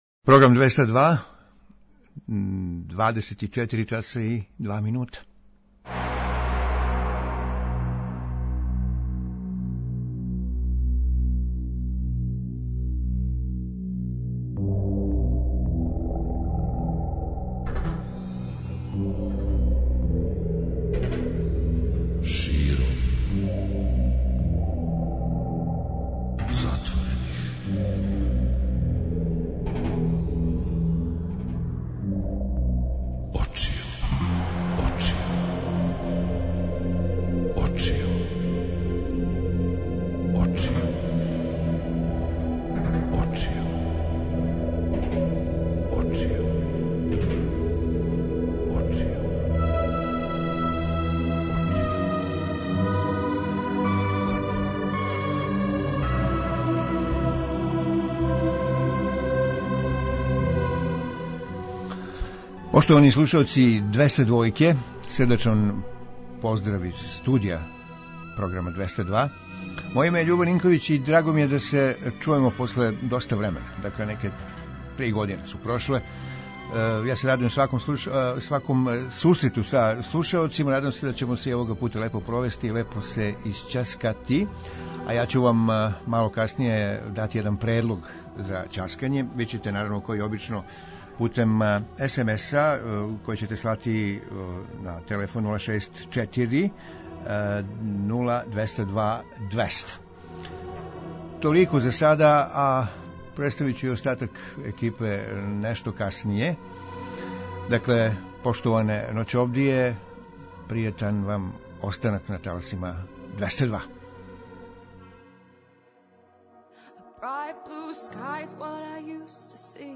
Предлог за наш ноћашњи разговор - пролеће у календару и пролеће у души, односиће се на промене у нама које доноси ово годишње доба, које је било инспирација многим уметницима за стварање великих дела. Квалитетна музика, која ће подржати ову тему се подразумева.